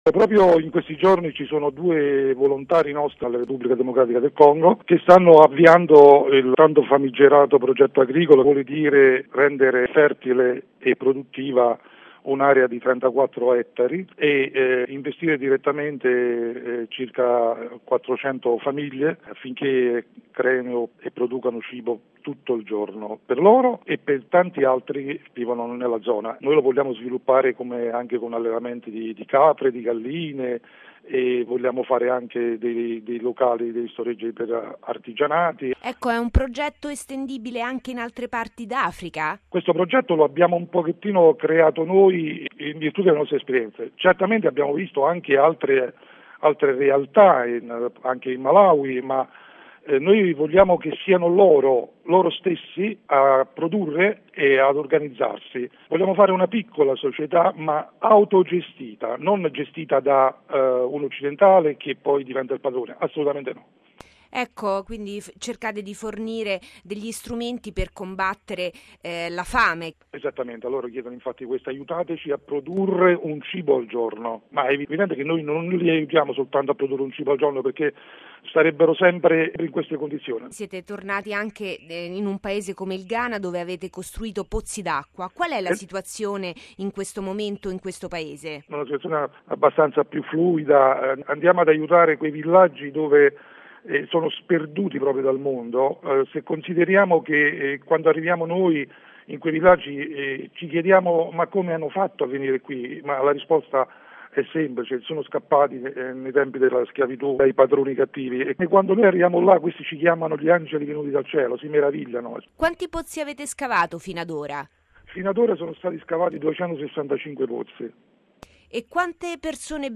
Radiogiornale del 12/04/2014 - Radio Vaticana